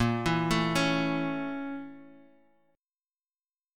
A#mM7b5 Chord